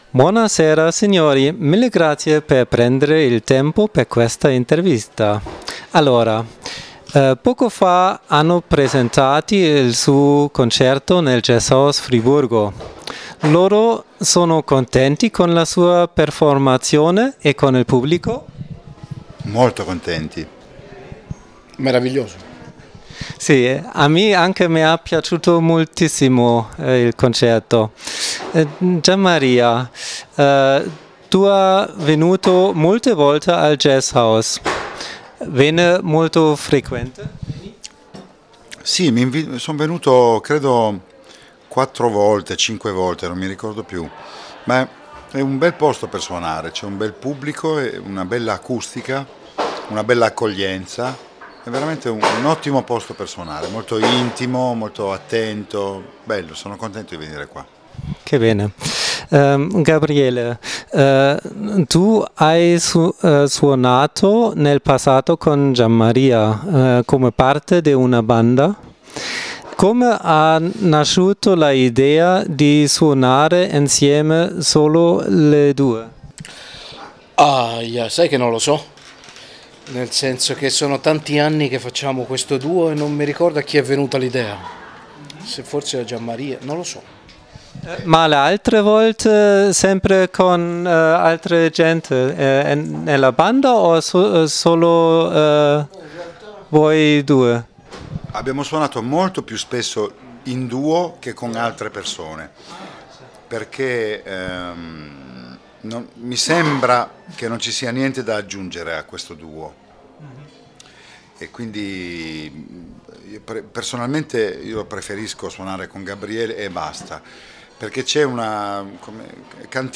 Interview mit dem italienischen Liedermacher Gianmaria Testa und dem Klarinettisten Gabriele Mirabassi
Am 28. Mai führte die MusikVote Redaktion nach einem praktisch ausverkauften Konzert im Jazzhaus ein Interview mit dem italienischen Liedermacher Gianmaria Testa und dem Klarinettisten Gabriele Mirabassi. Die Musik der Künstler und die Notwendigkeit, auch politische Themen in den Liedern zu behandeln, stand im Vordergrund.
interview_italienisch.mp3